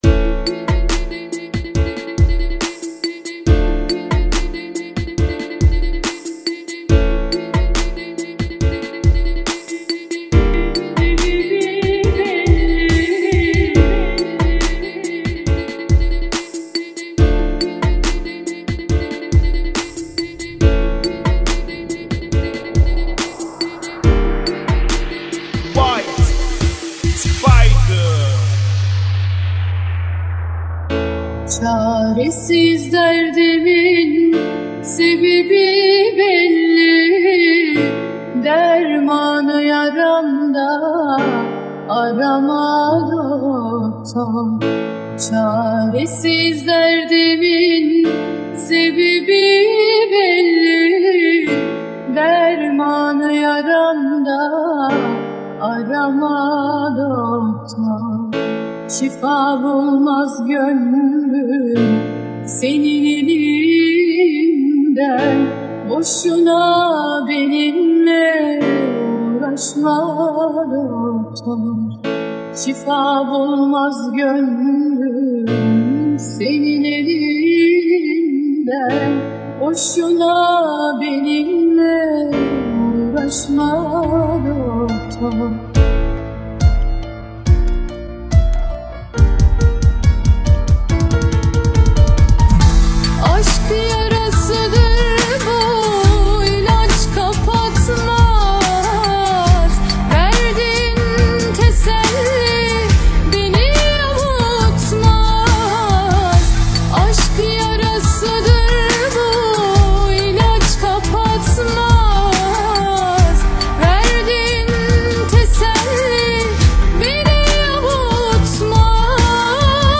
Funky [ 70 Bpm ]